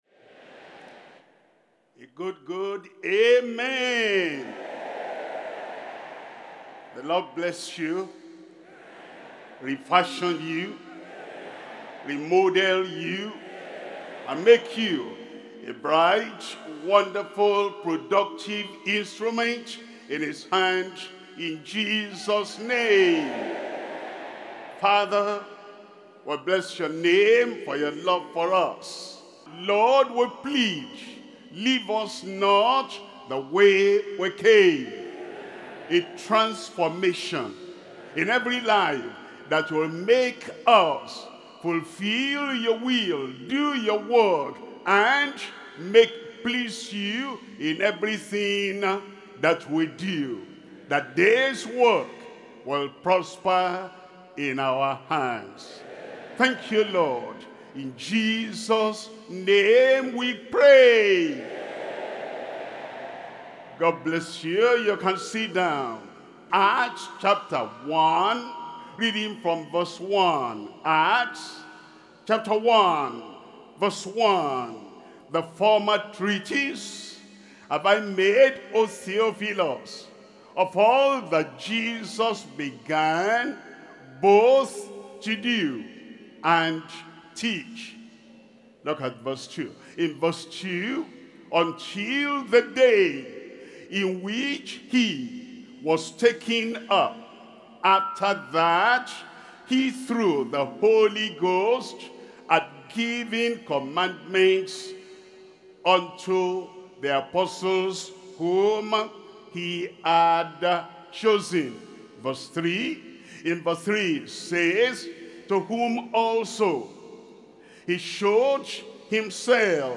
Sermons – Deeper Christian Life Ministry, Merseyside & Wales Region